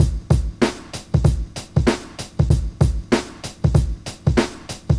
• 96 Bpm Old School Hip-Hop Drum Loop A Key.wav
Free drum beat - kick tuned to the A note. Loudest frequency: 979Hz
96-bpm-old-school-hip-hop-drum-loop-a-key-O0N.wav